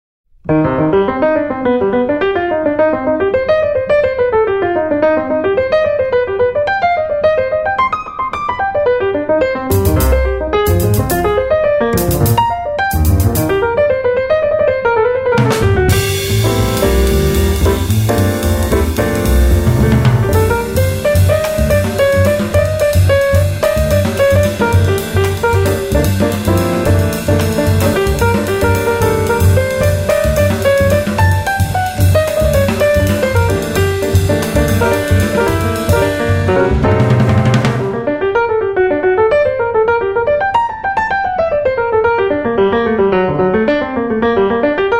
piano
bass
drums
美しきクラシックのスタンダード・チューンをス インギンなジャズのフィーリングで力強く、そして優雅にプレイ！